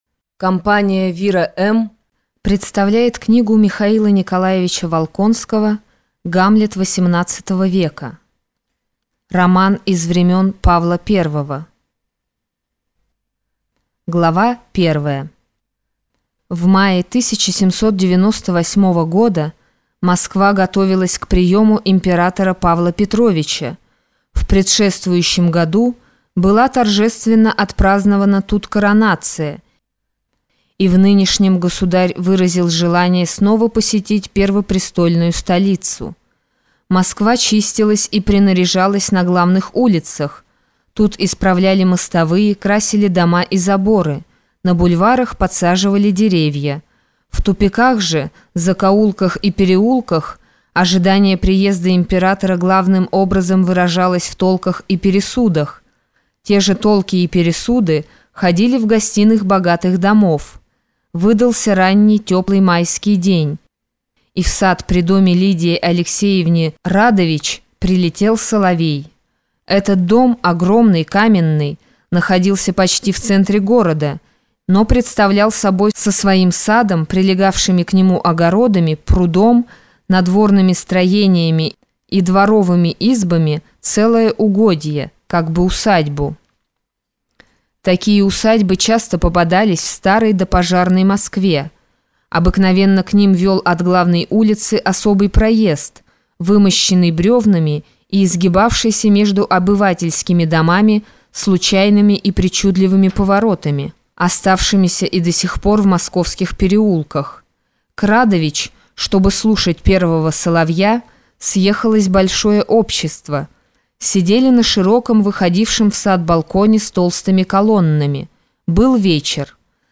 Аудиокнига Гамлет 18 века | Библиотека аудиокниг
Прослушать и бесплатно скачать фрагмент аудиокниги